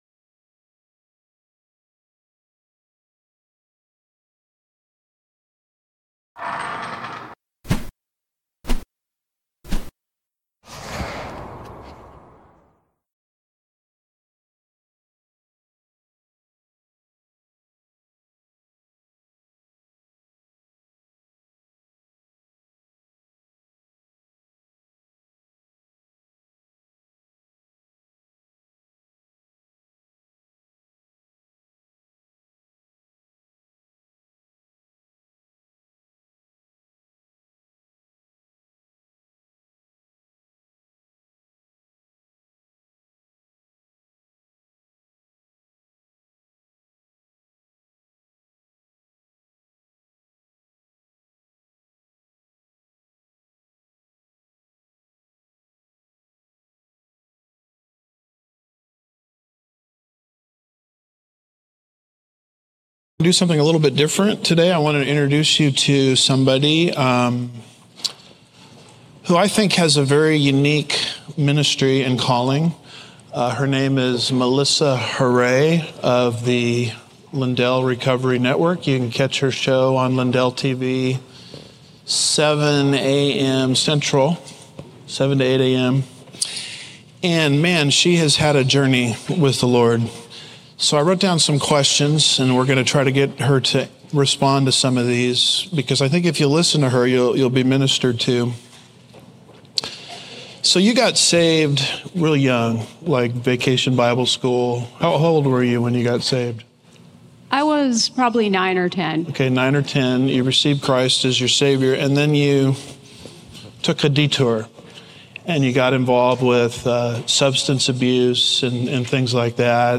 Special Interview
2026 Prophecy Conference